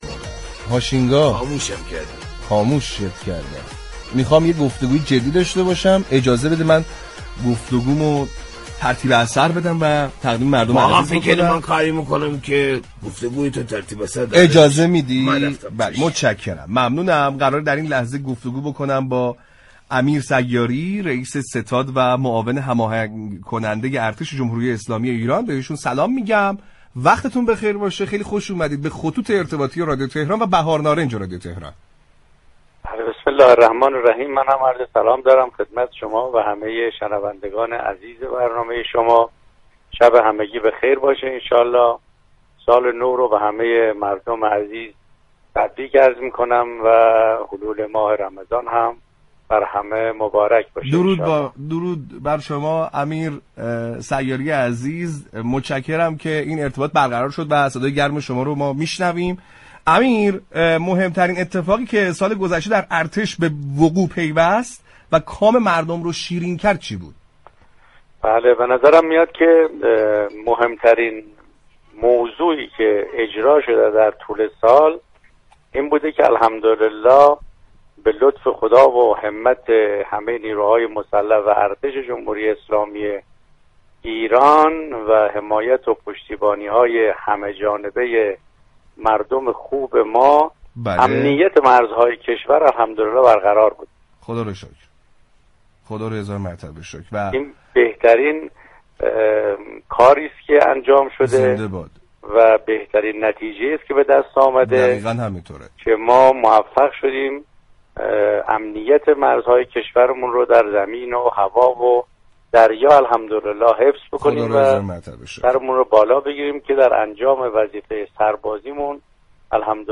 به گزارش پایگاه اطلاع رسانی رادیو تهران، امیر حبیب الله سیاری رئیس ستاد و معاون هماهنگ كننده ارتش جمهوری اسلامی ایران در گفت و گو با برنامه «بهار نارنج» شبكه رادیویی تهران كه در لحظات منتهی به تحویل سال نو پخش شد در خصوص مهمترین اقدامات ارتش كه كام مردم را دلنشین كرد گفت: تامین امنیت تمامی مرزهای زمینی، دریایی و هوایی كشور توسط نیروهای مسلح و حمایت همه جانبه مردم كشور ؛ بهترین نتیجه ای است كه در سال پیش حاصل شد.